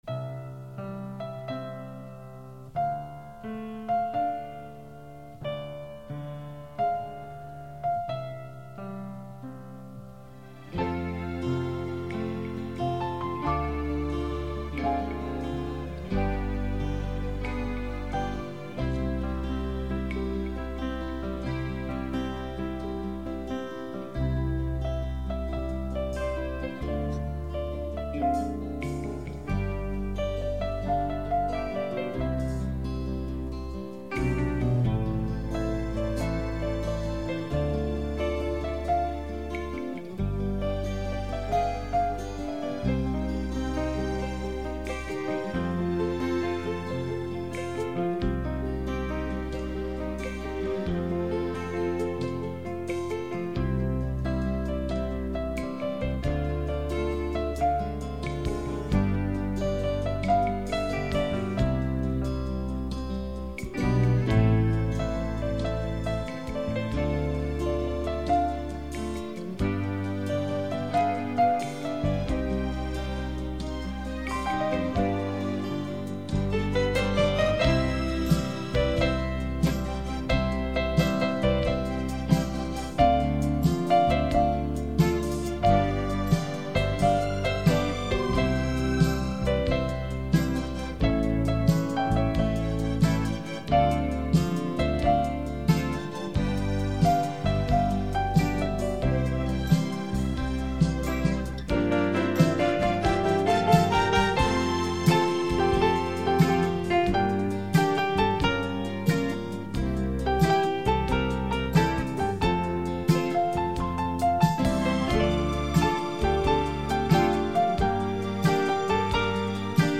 刻 痕 - 演奏曲